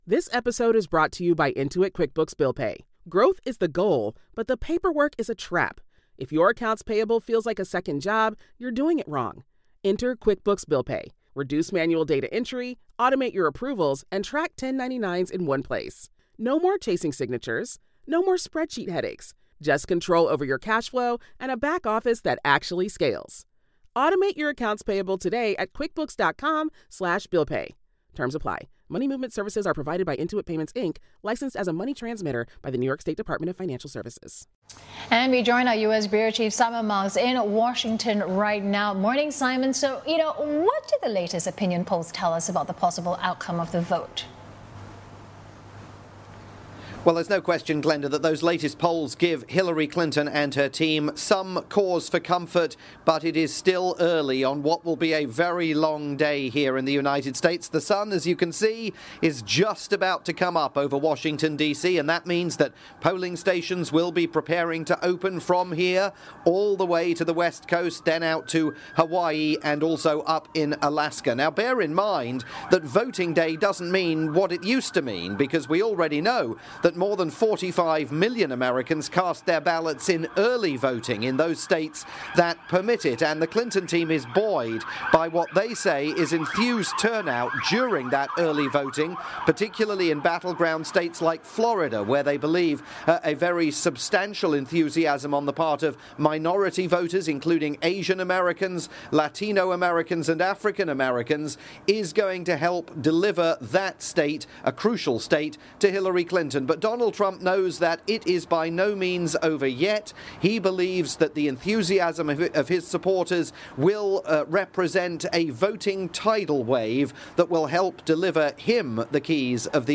dawn report from Washington for Channel NewsAsia on the arrival....finally...of Election Day.